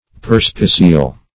Search Result for " perspicil" : The Collaborative International Dictionary of English v.0.48: Perspicil \Per"spi*cil\ (p[~e]r"sp[i^]*s[i^]l), n. [LL. perspicilla, fr. L. perspicere to look through.] An optical glass; a telescope.